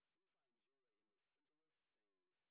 sp07_white_snr30.wav